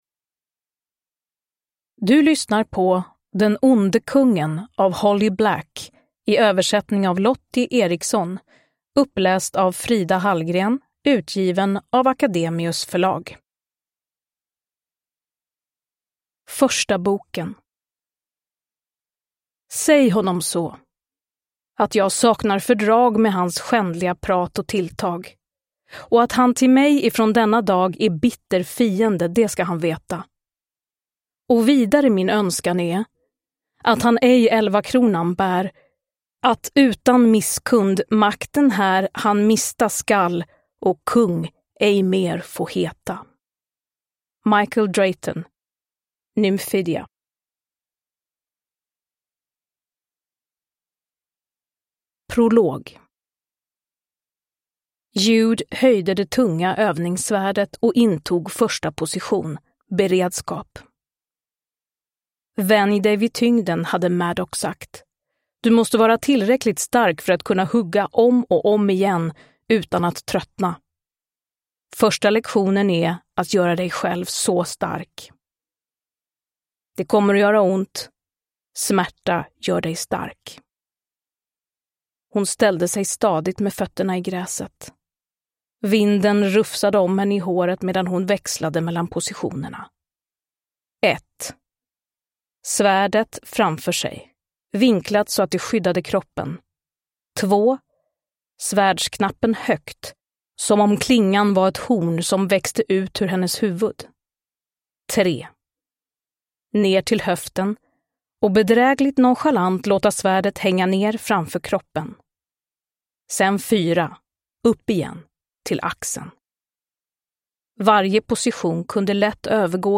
Den onde kungen – Ljudbok
Uppläsare: Frida Hallgren